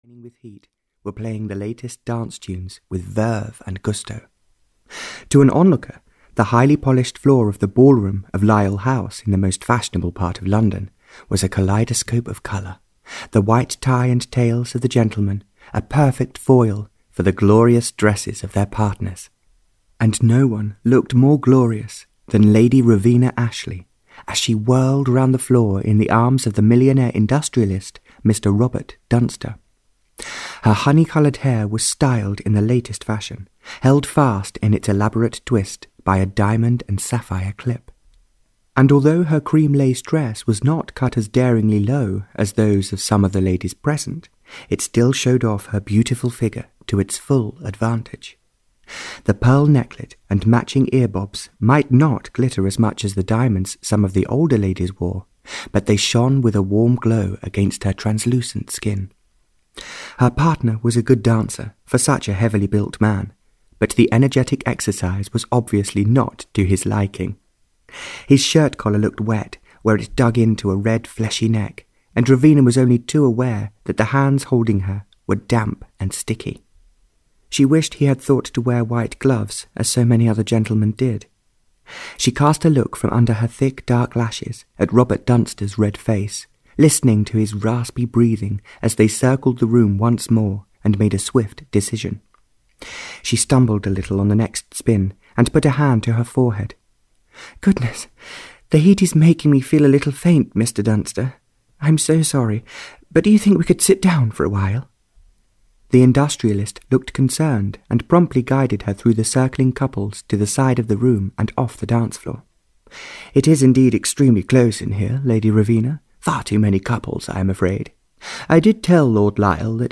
Audio knihaAn Unexpected Love (Barbara Cartland’s Pink Collection 33) (EN)
Ukázka z knihy